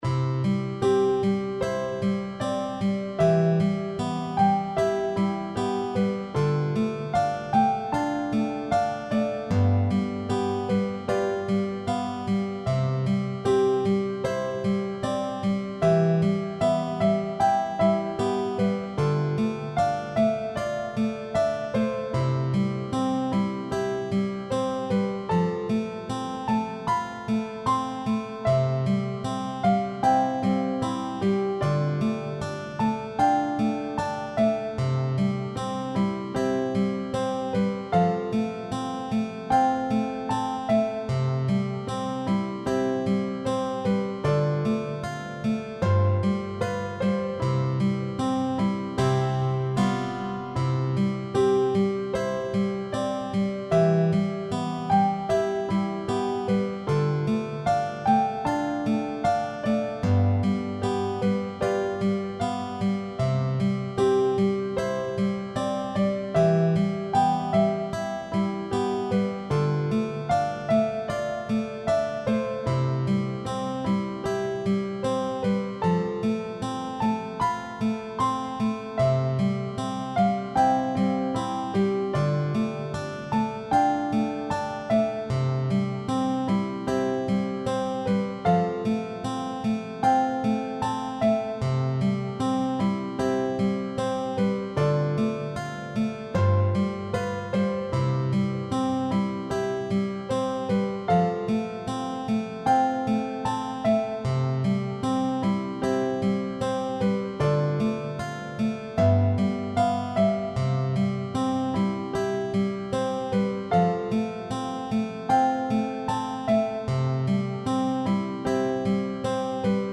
ハ長調、4分の4拍子。
Instrumentation (楽器編成) Vocal, Folk Guitar, Piano Published (出版) 2020年04月18日に、このウェブページで楽譜ファイルを公開。
Instrumental (Vocal パート: ピアノ)